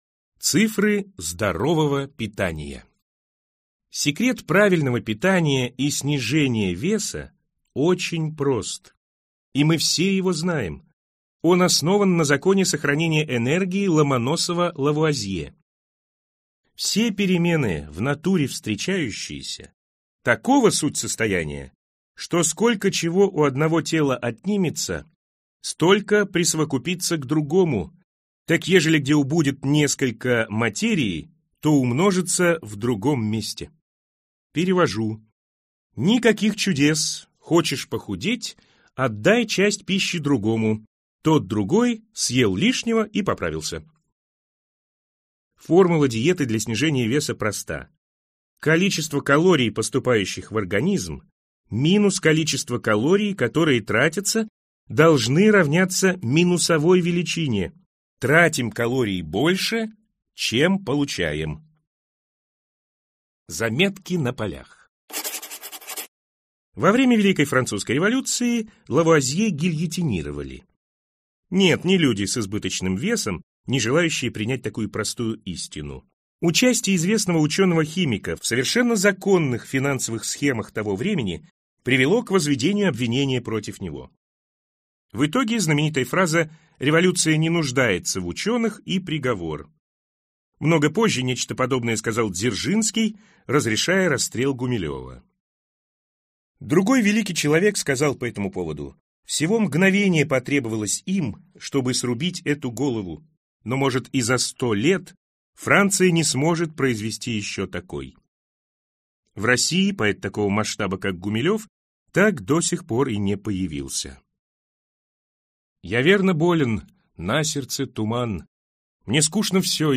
Аудиокнига Пищеводитель - купить, скачать и слушать онлайн | КнигоПоиск